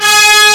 HORNENSMLOOP.wav